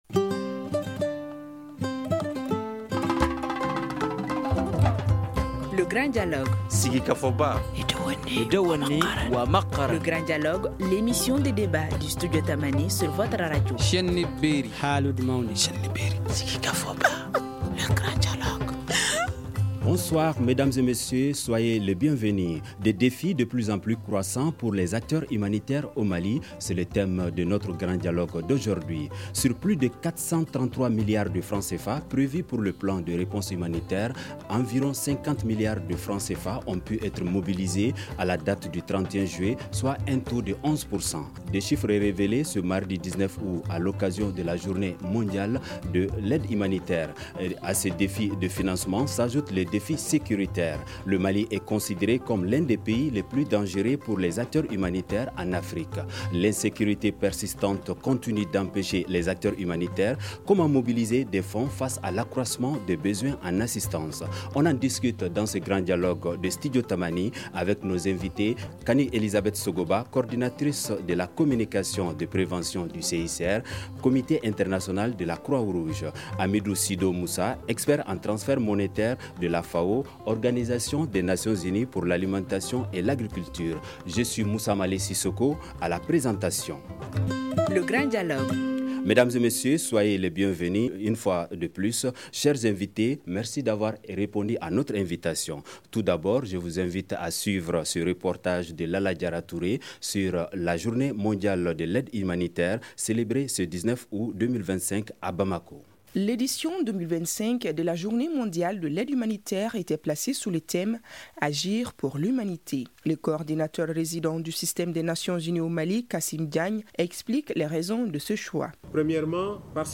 Comment mobiliser des fonds face à l’accroissement de besoins en assistance ? On en discute dans ce Grand Dialogue de Studio Tamani.